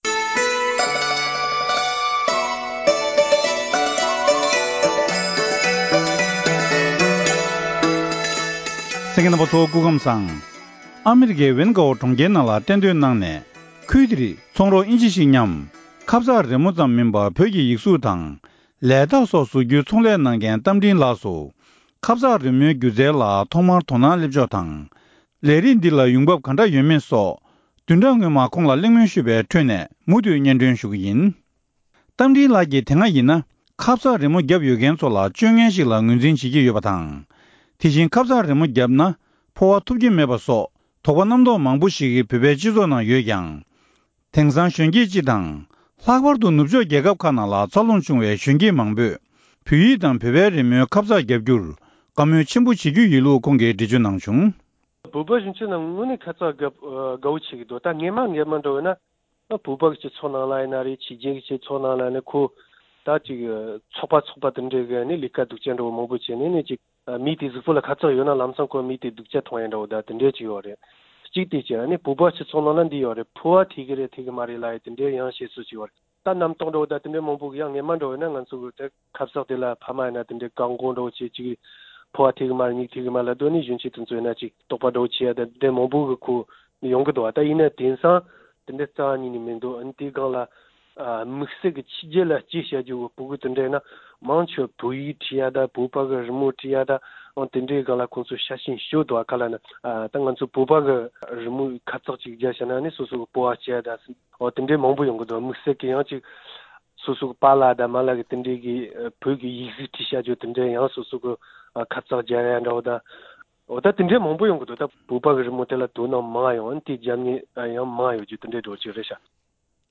སྒྲ་ལྡན་གསར་འགྱུར།
བཀའ་འདྲི་ཞུས་པར་གསན་རོགས་ཞུ༎